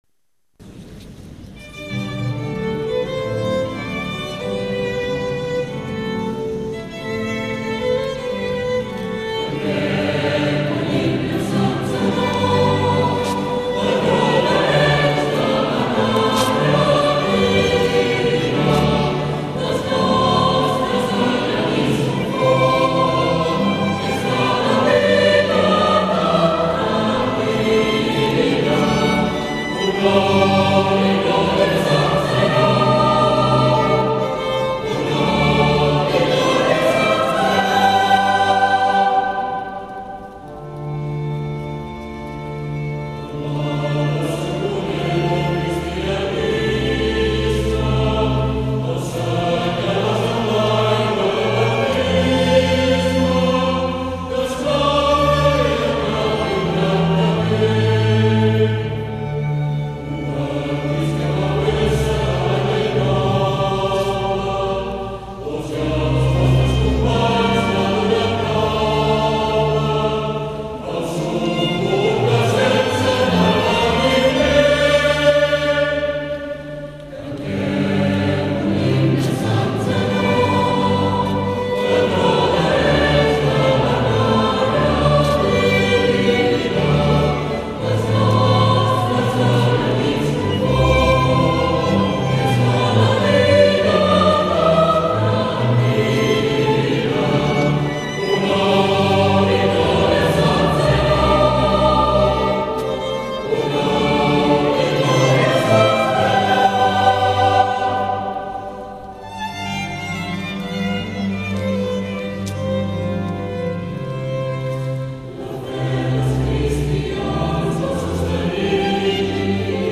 El tret de sortida de la Festa Major d’Arenys de Mar no és el pregó o el brindis popular, sinó el cant de l’Himne a Sant Zenon, de Joan Draper i Xavier Maimí, cantat el dia 8 de juliol en acabar les Completes.
a:1:{s:11:"description";s:56:"Himne a Sant Zenon interpretat pel Cor l'Aixa l'any 2005";}